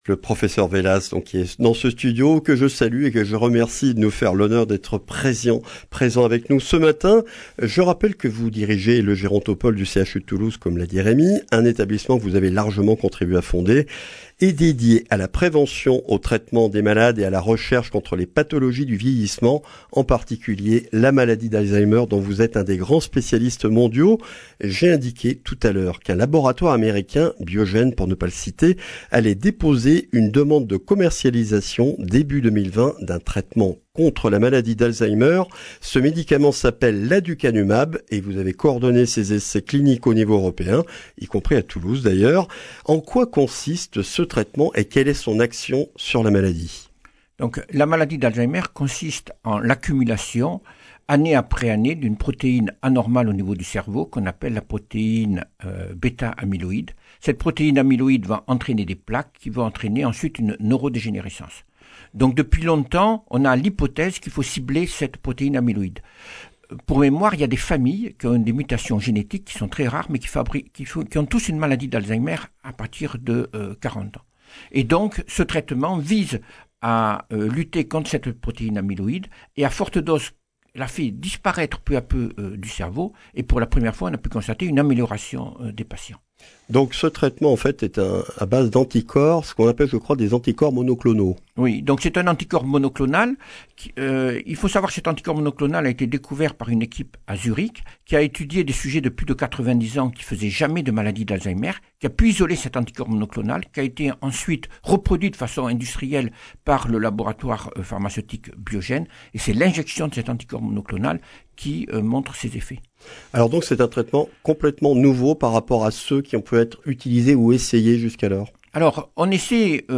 Le grand entretien